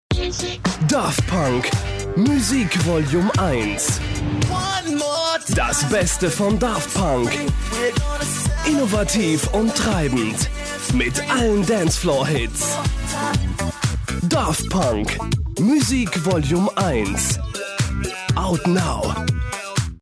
deutscher Sprecher, helle bis mittlere Stimmlage: gerne eingesetzt für Funk- und TV-Werbung, Off-Stimme, Voice Over
Kein Dialekt
Sprechprobe: Sonstiges (Muttersprache):